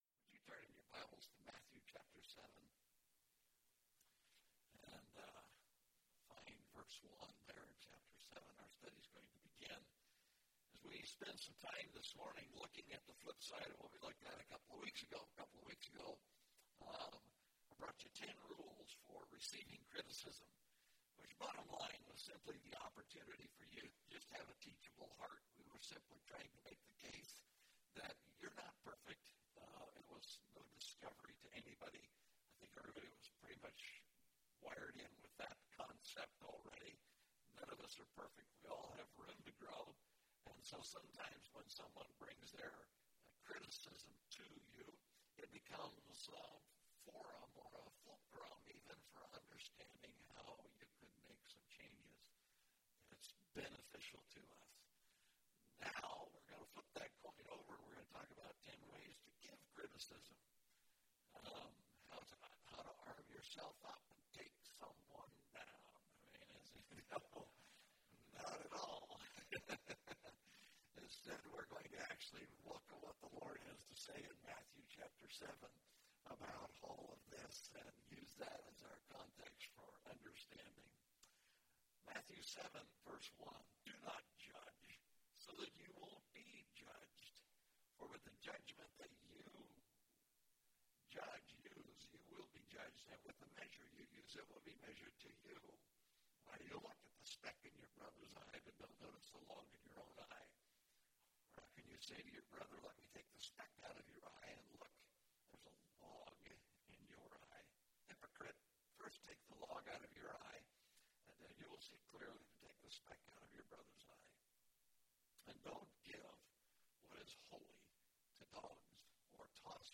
Ten Rules for Giving Criticism (Matthew 7:1-6) – Mountain View Baptist Church